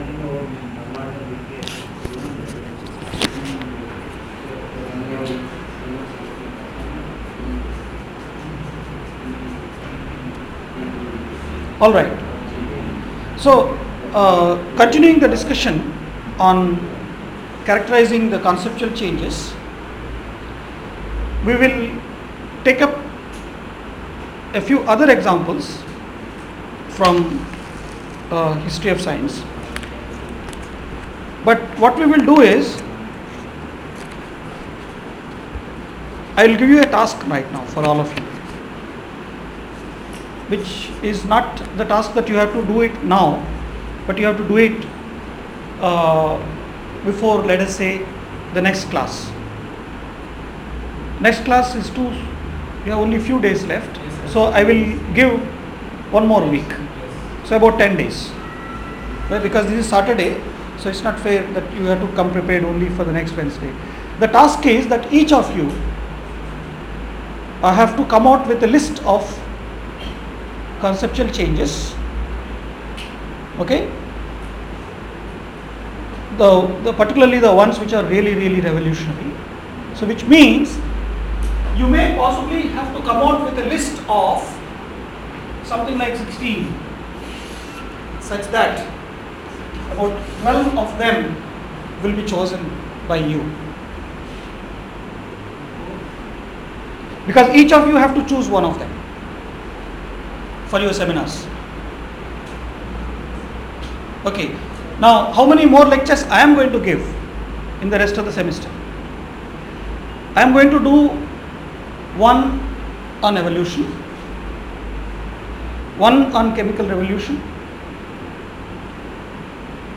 lecture 15 — Invitation to History of Science: H201